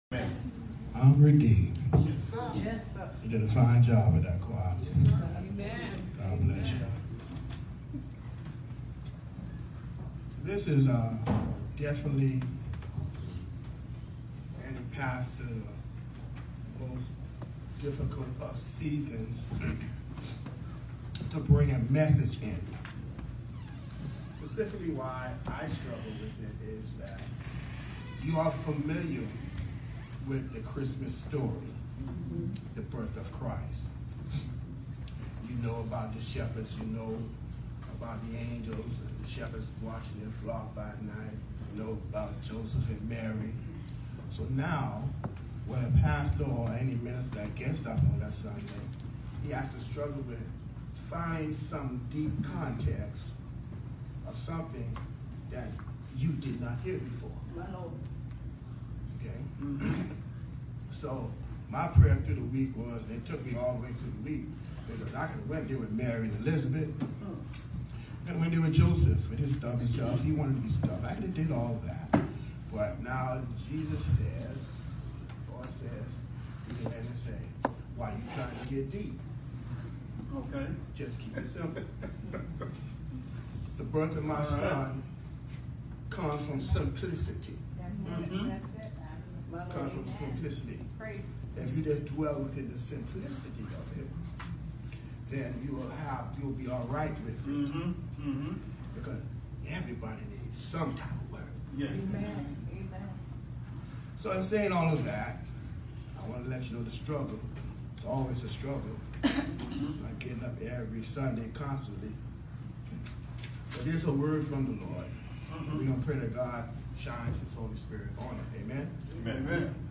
DECEMBER 23, 2018 SUNDAY 11:00am NEW JERUSALEM MB CHURCH John 1:1-14 The Message: I’LL BE HOME FOR CHRISTMAS”